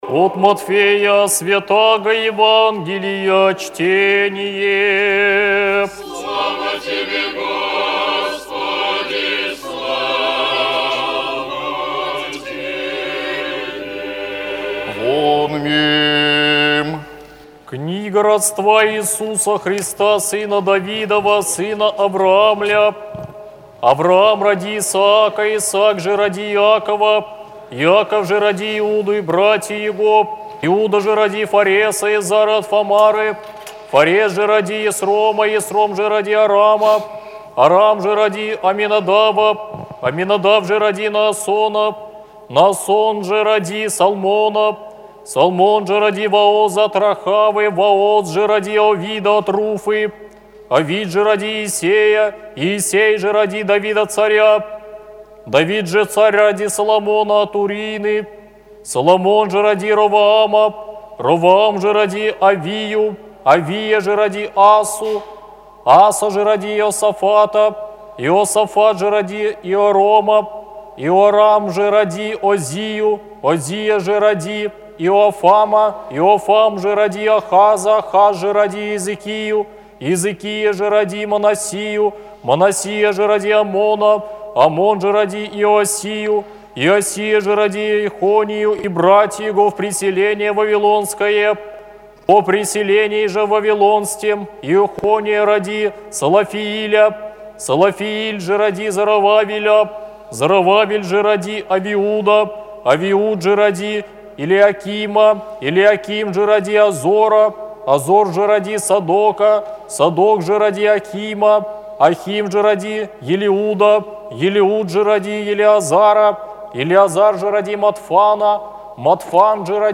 Евангельское чтение на литургии Аудио запись